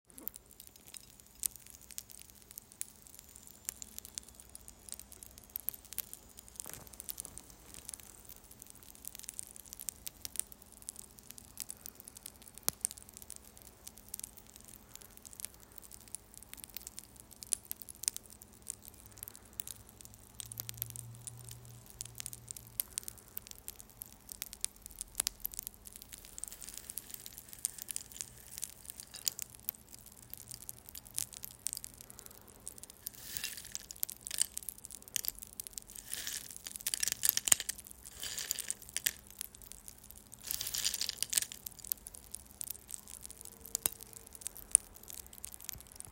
Coins Shaking
Title en Coins Shaking Description en Medieval sound of coins ContentConcept en Medievalsound File Date en 2025-09-19 Type en Audio Tier en 8.